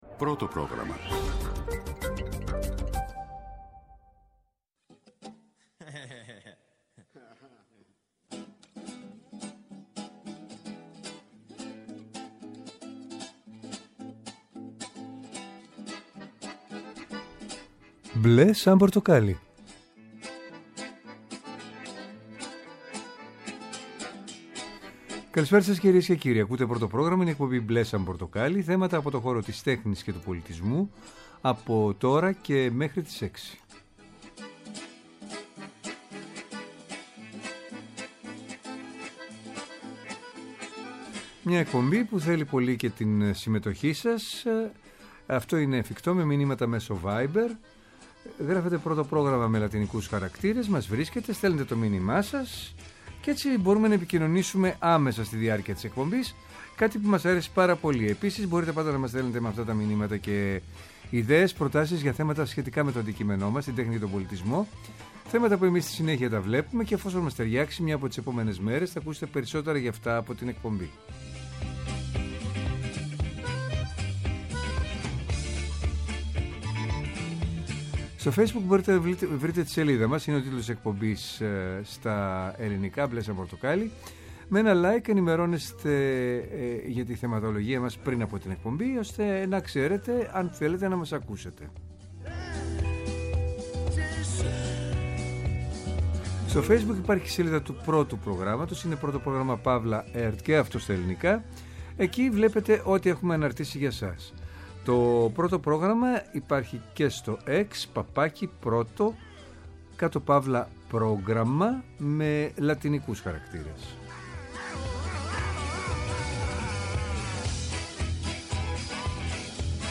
Τηλεφωνικά καλεσμένοι μας είναι οι:
Μια εκπομπή με εκλεκτούς καλεσμένους, άποψη και επαφή με την επικαιρότητα.